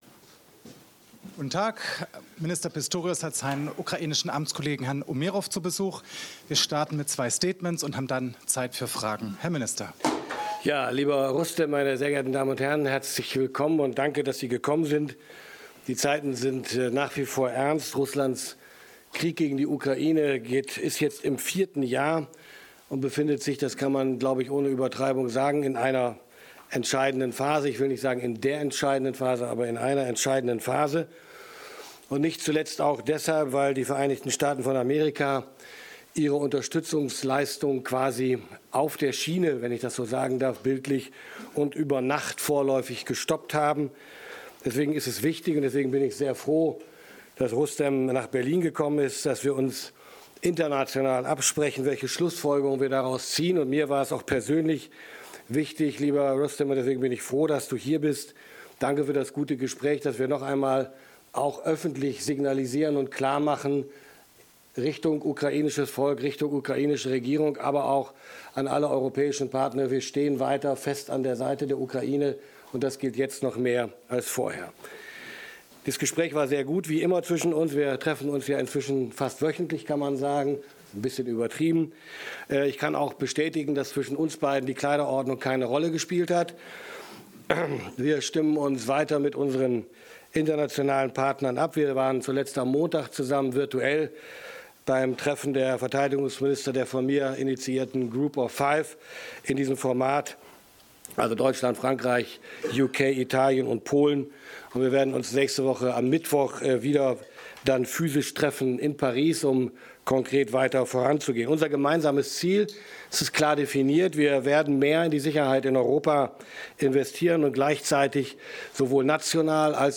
Fürs Archiv: Die Pressekonferenz von Verteidigungsminister Boris Pistorius und seinem ukrainischen Kollegen Rustem Umjerow am (heutigen) Donnerstag in Berlin zum Nachhören: Auch wenn Pistorius zu Beginn anmerkte, beide Minister hätten keine Probleme mit der Kleiderordnung gehabt (vgl. Foto oben), im Mittelpunkt standen schon sehr ernste Dinge.